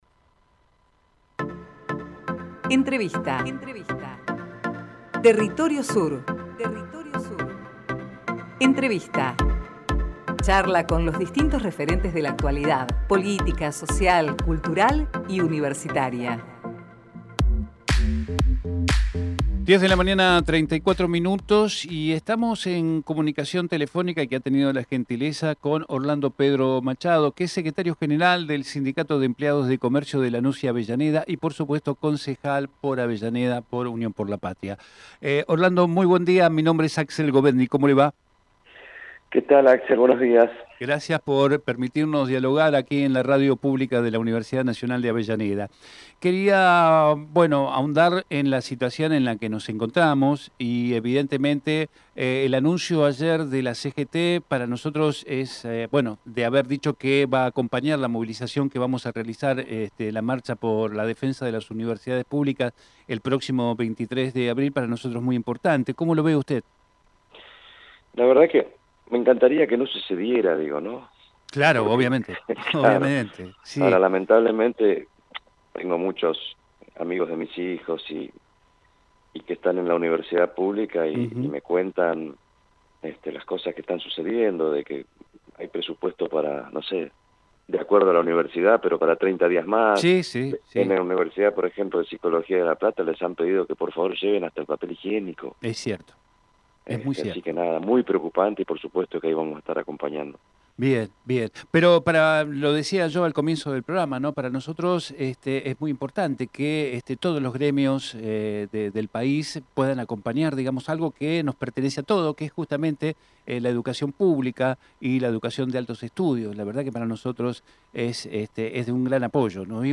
TERRITORIO SUR - ORLANDO PEDRO MACHADO Texto de la nota: Compartimos la entrevista realizada en Territorio Sur a Orlando Pedro Machado, Secretario General del sindicato de empleados de comercio de Lanús y Avellaneda y concejal por Avellaneda.